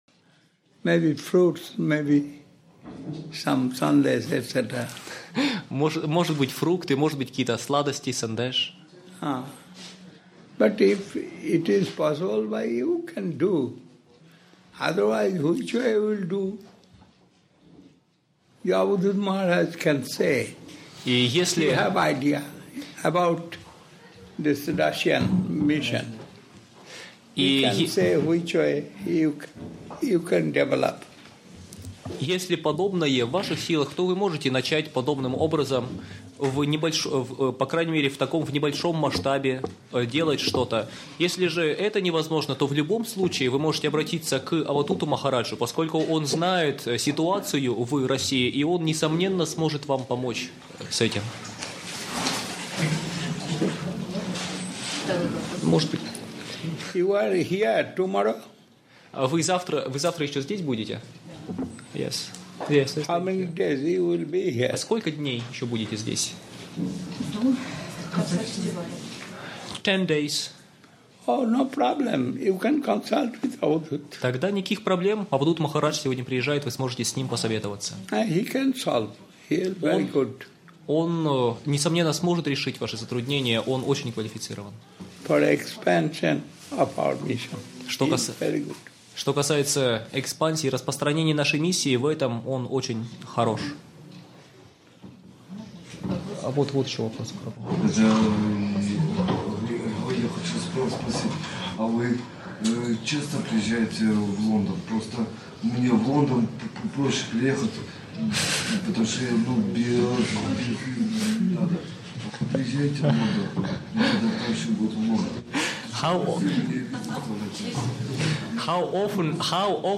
Место: Лахтинский Центр Бхакти йоги